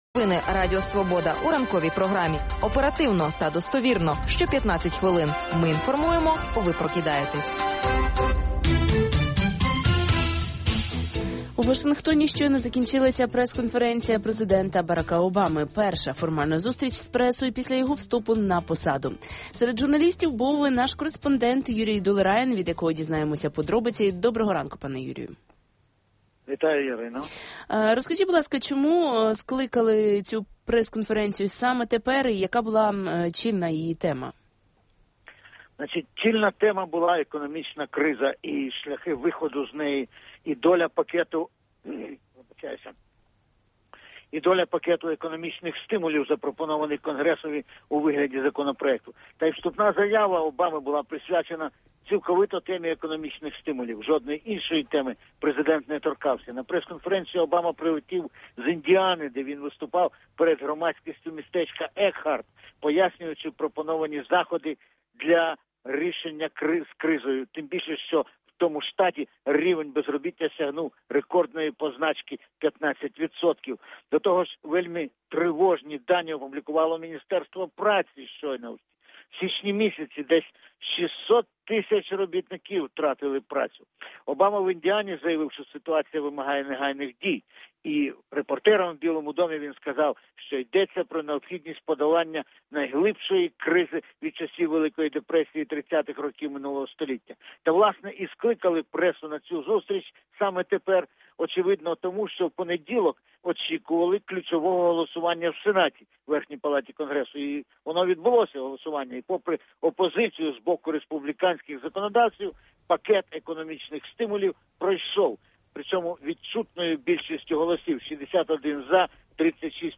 Перша прес-конференція Президента США Барака Обама